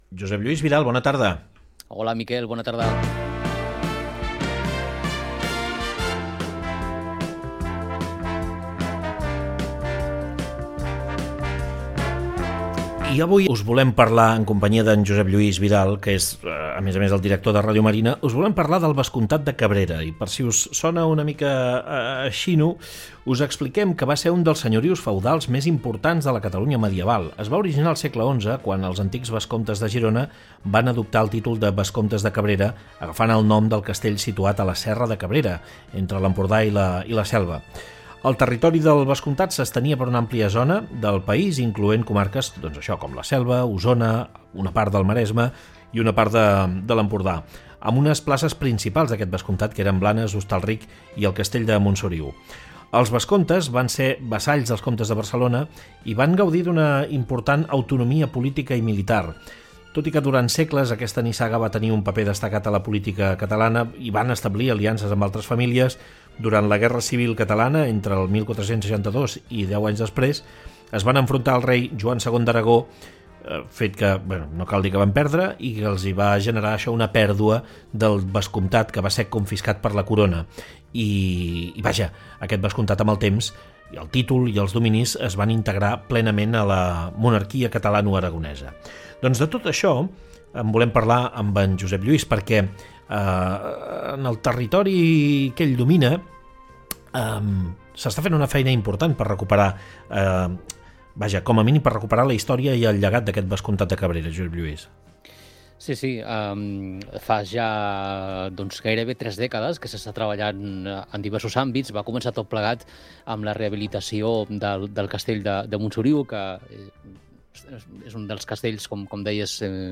En una entrevista a De cap a cap